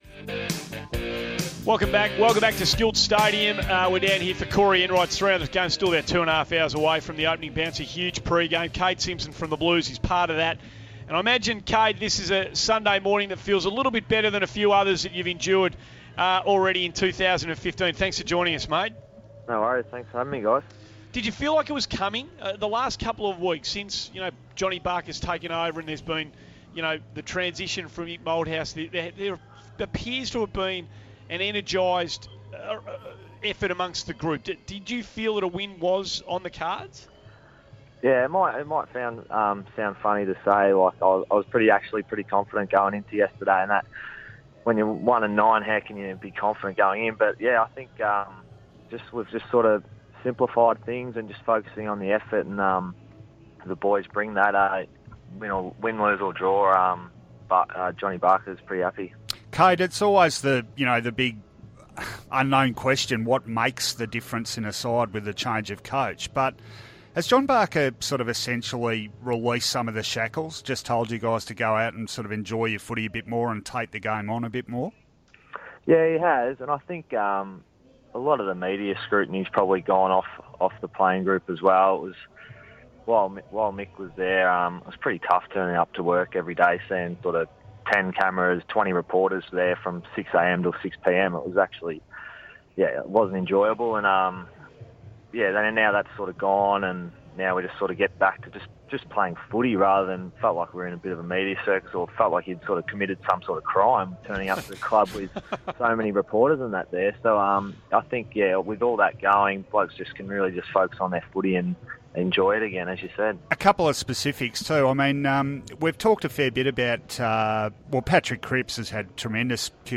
Carlton midfielder Kade Simpson talks to SEN 1116 after the Blues' thrilling victory over Port Adelaide.